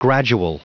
Prononciation du mot gradual en anglais (fichier audio)
Prononciation du mot : gradual
gradual.wav